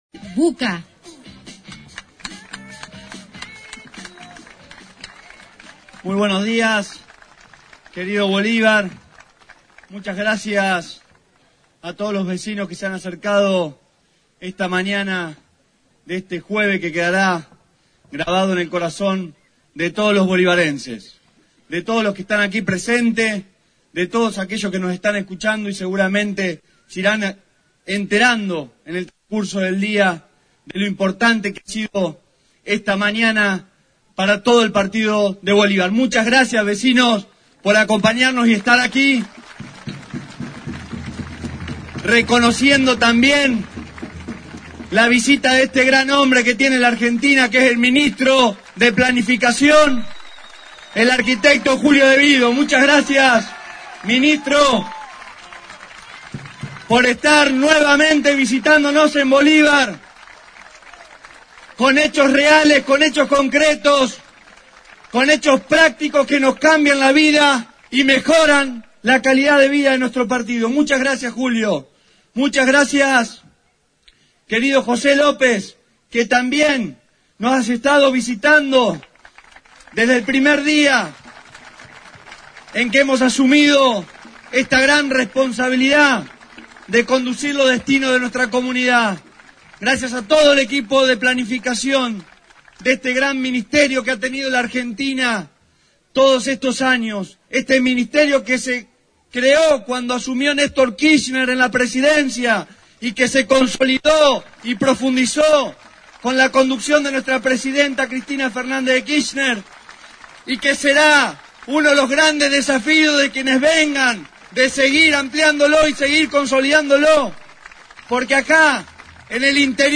AUDIO DEL ACTO